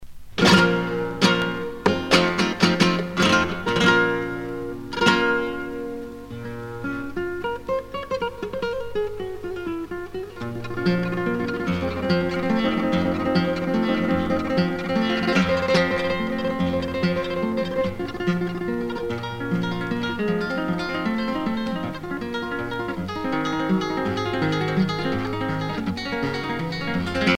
danse : flamenco
Pièce musicale éditée